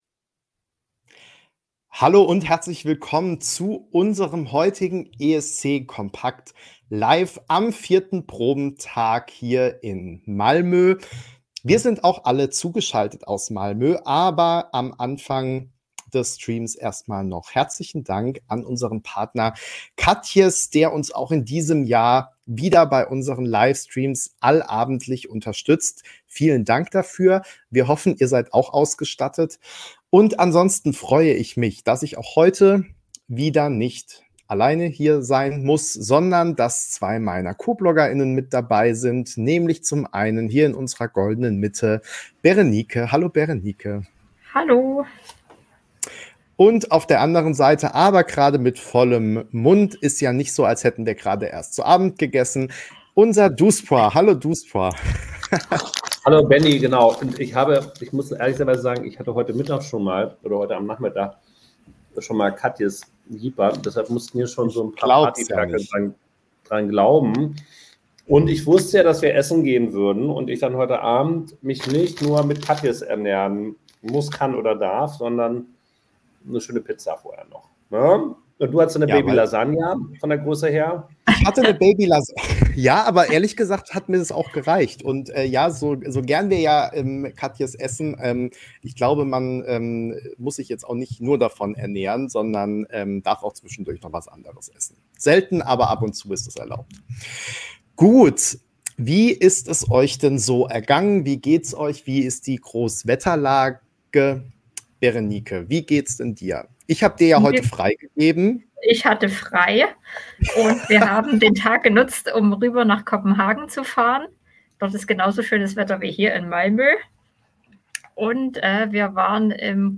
ESC kompakt LIVE